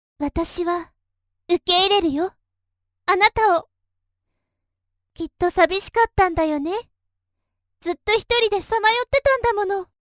元気系キャラ。
ボイスサンプルA